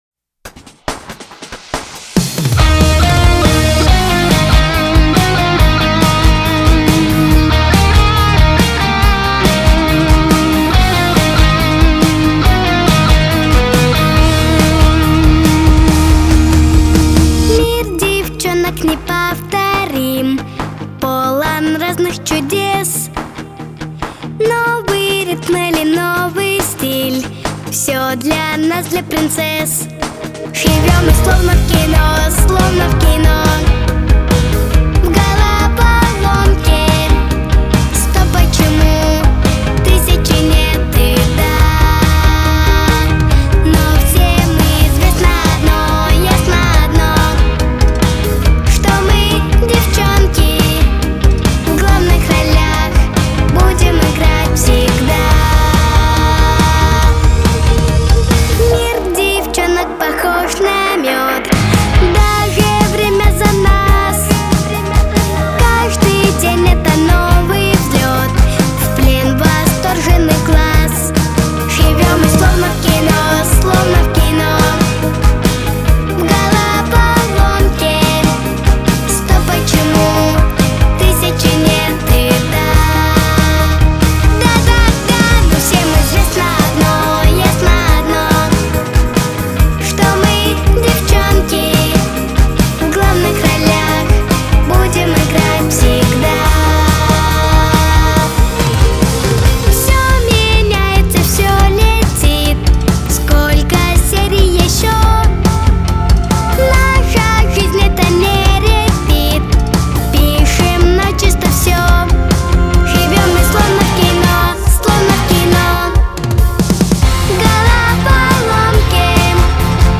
Характер песни: весёлый.
Темп песни: быстрый.